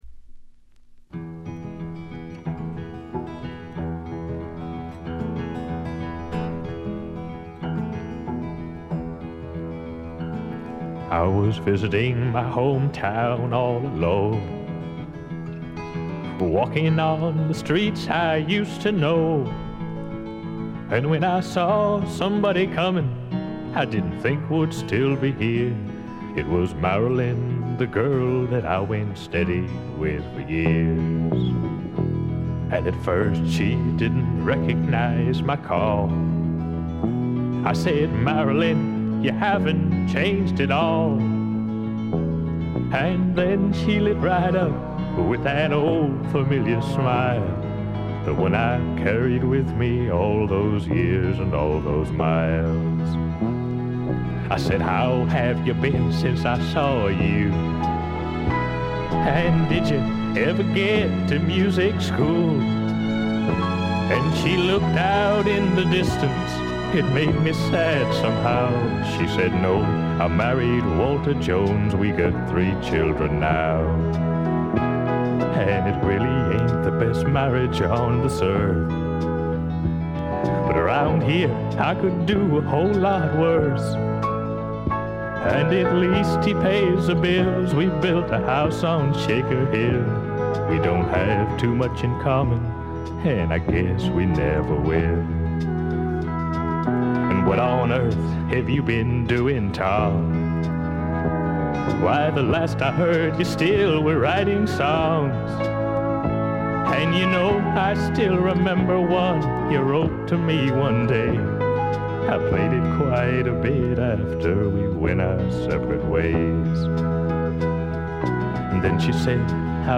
部分試聴ですがごくわずかなノイズ感のみ。
70年代シンガー・ソングライター・ブームが爛熟期を迎え、退廃に向かう寸前に発表されたフォーキーな名作です。
シンガー・ソングライター基本盤。
試聴曲は現品からの取り込み音源です。
Guitar
Piano
Drums
Bass
Background Vocal